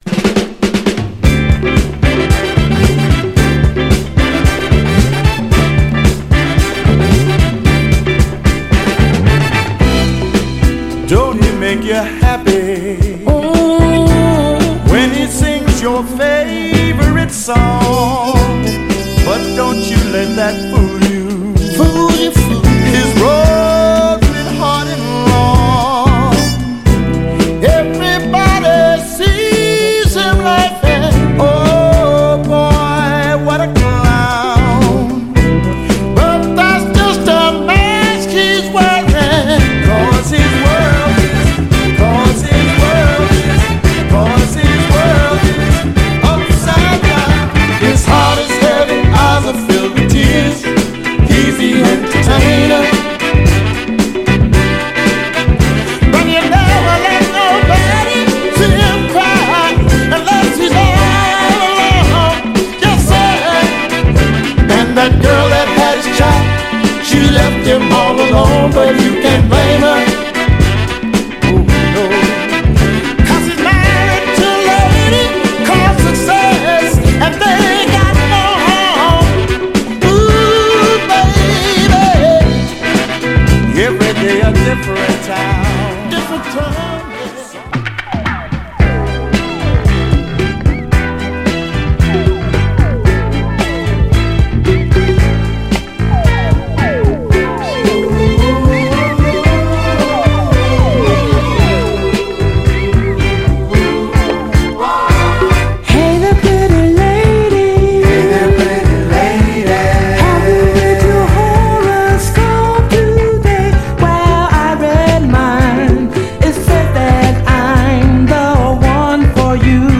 華やかなアレンジで爽快感溢れる、モダンソウル/クロスオーヴァー・ダンサー名曲
甘茶なファルセットで聴かせるメロウ・ステッパー
両面共に爽やかで程よくキャッチー、そしてアーバンなモダン・ソウルでオススメです！
※試聴音源は実際にお送りする商品から録音したものです※